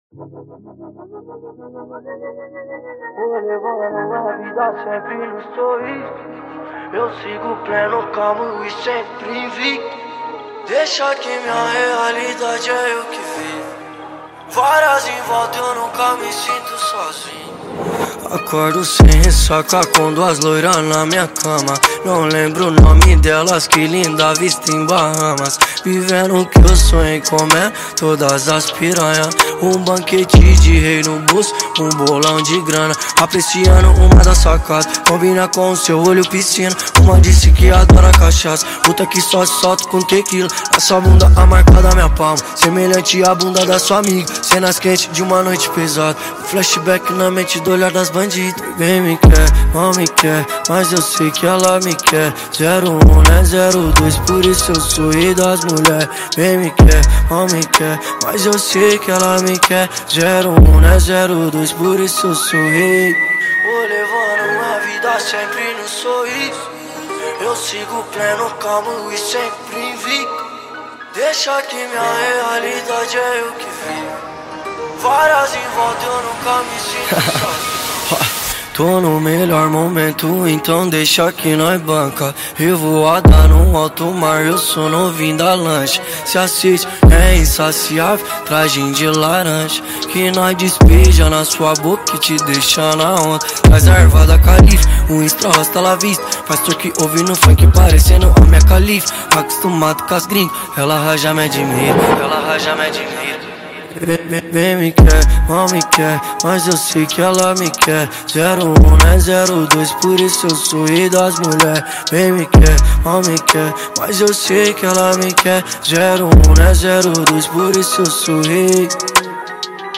2024-02-16 22:35:48 Gênero: Funk Views